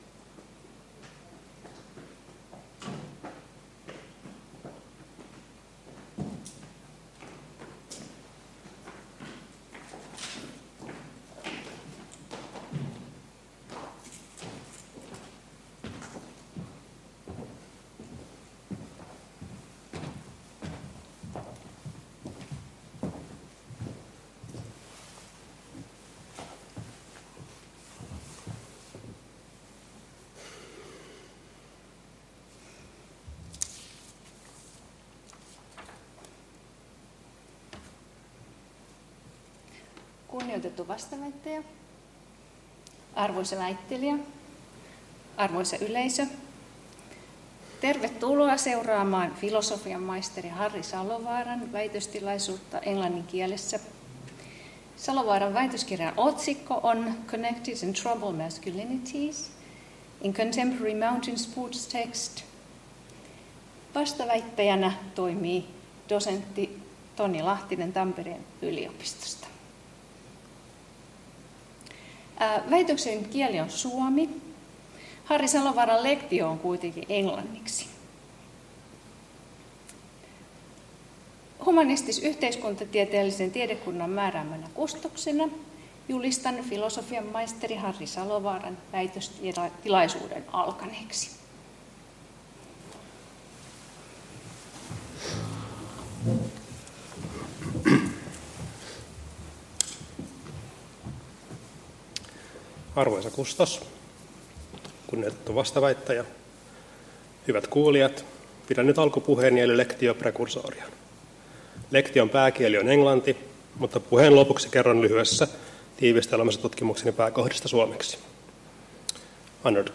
väitöstilaisuus